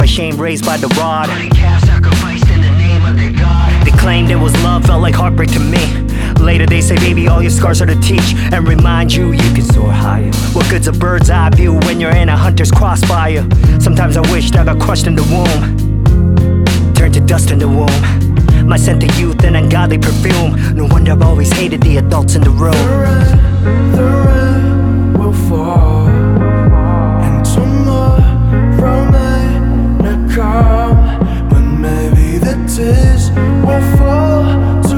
Hip-Hop Rap Pop K-Pop
Жанр: Хип-Хоп / Рэп / Поп музыка